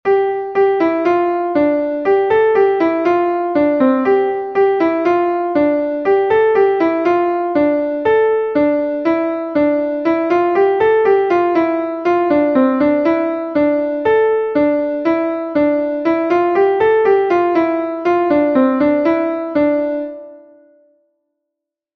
a Rond from Brittany